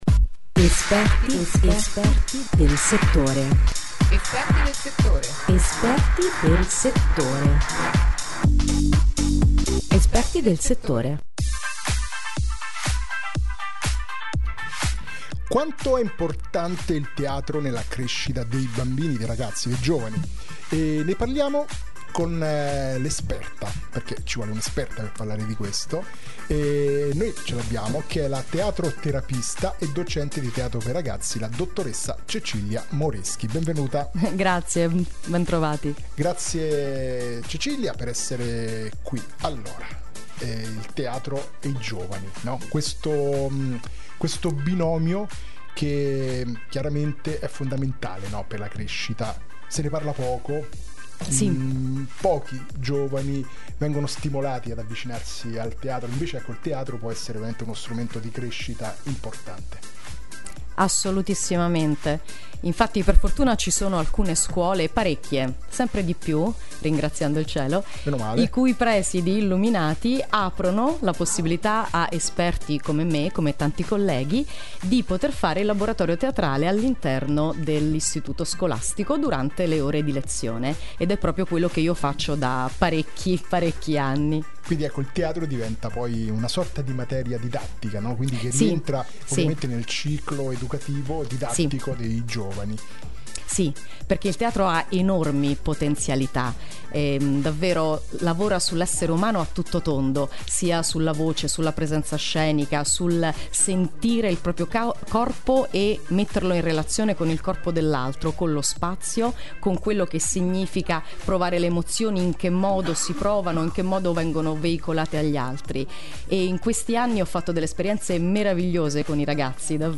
Intervista sul Teatro Ragazzi